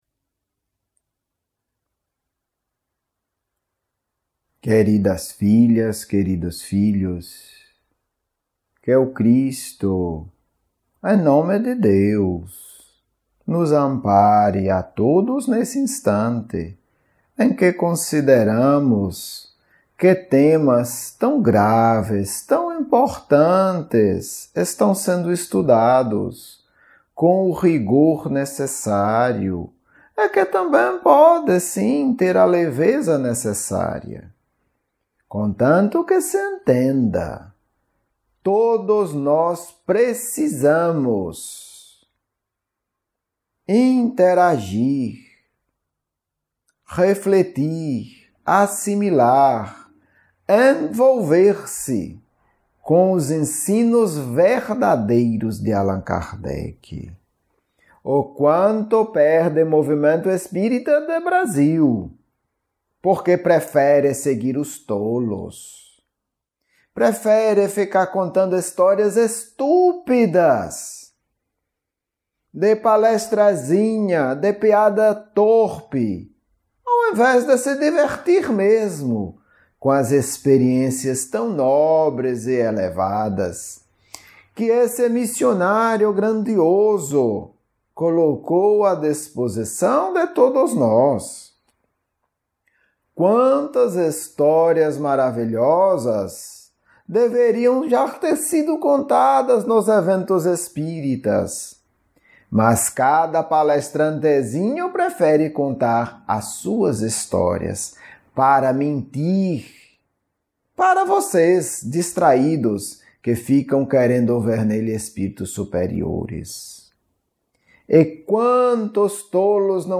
Estudo 3 – Pontos magnéticos centrais e ansiedade 2 Diálogo mediúnico